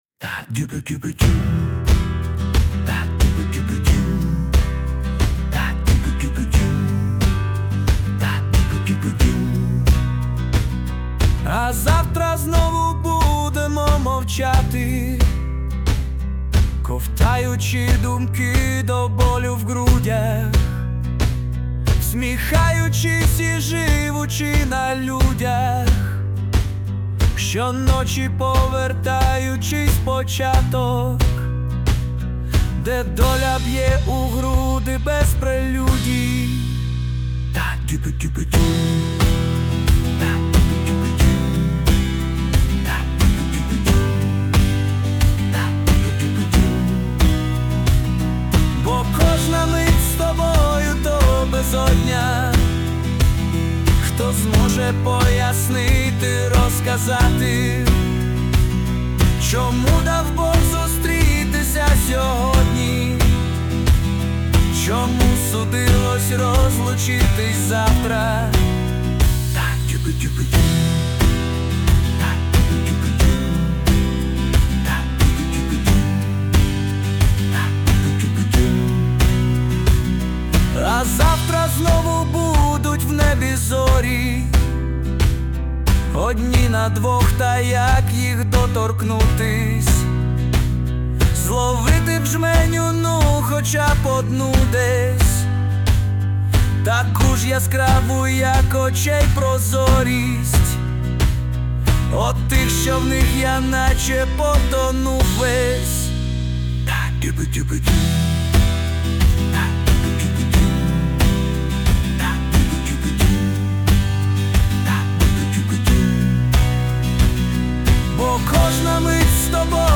СТИЛЬОВІ ЖАНРИ: Ліричний
ВИД ТВОРУ: Пісня